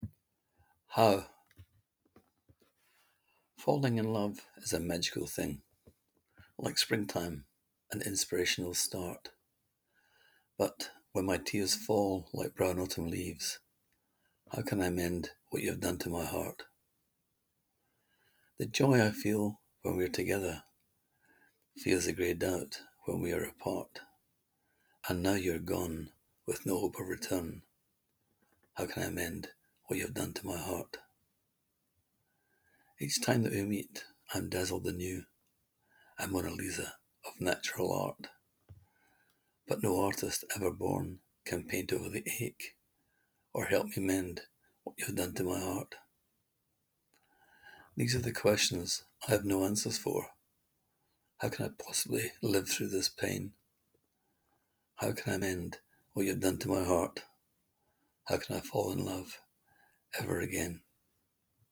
Click here to hear the poet read his words: